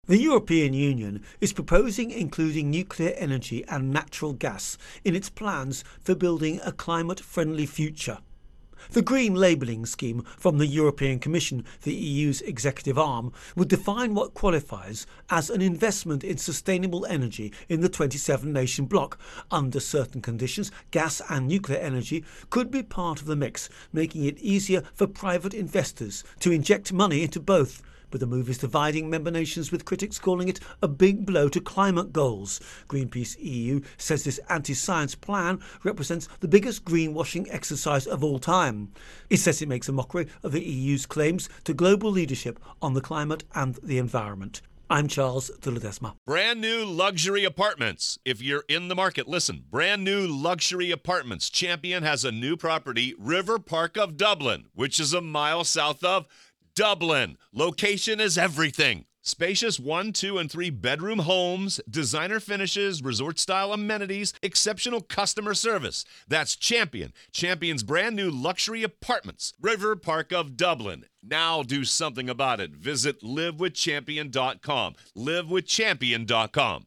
Climate Green Investment Intro and Voicer